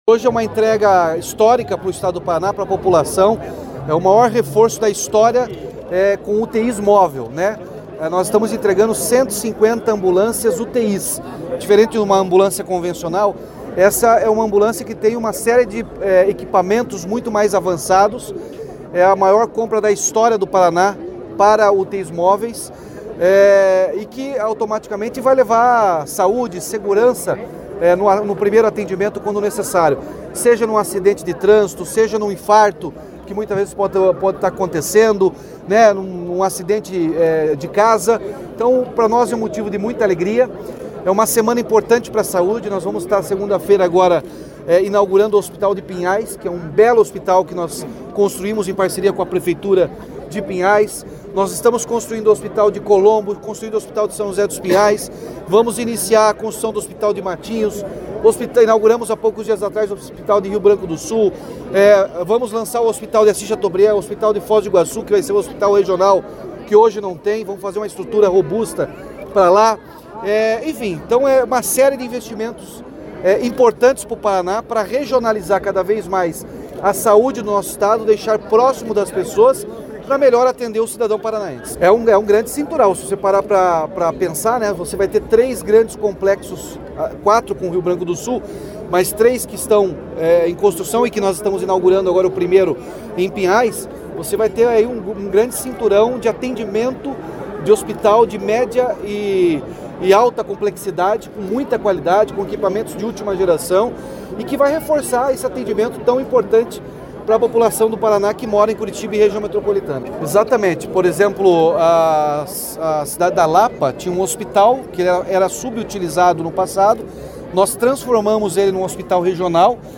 Sonora do governador Ratinho Junior sobre a entrega de 150 ambulâncias com UTIs para todas as regiões do Paraná